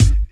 • 90s Studio Hip-Hop Kick Drum Single Hit G Key 302.wav
Royality free bass drum single hit tuned to the G note. Loudest frequency: 617Hz